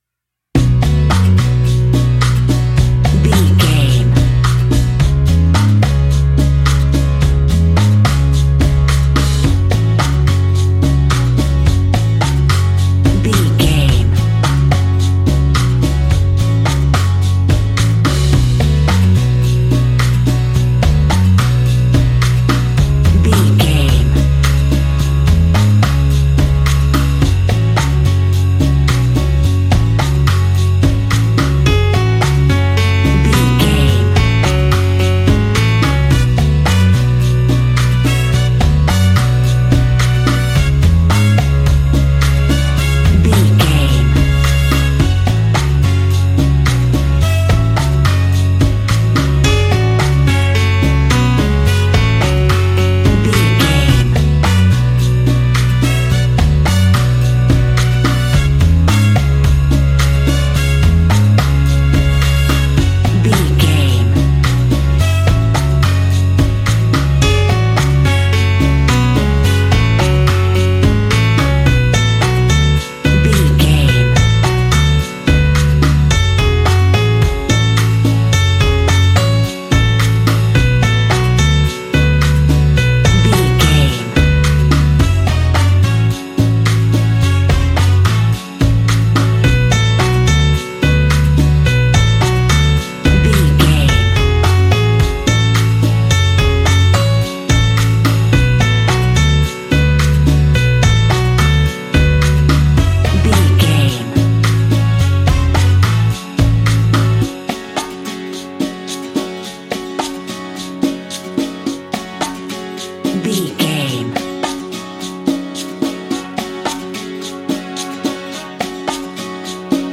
Aeolian/Minor
F#
calypso
steelpan
drums
percussion
bass
brass
guitar